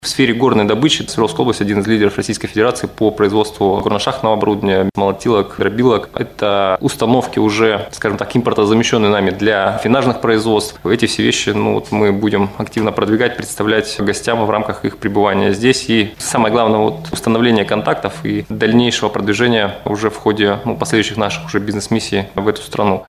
Регион готов поставлять оборудование. Об этом в преддверии «Иннопрома» рассказал заместитель министра международных связей Свердловской области Олег Александрин на пресс-конференции «ТАСС-Урал».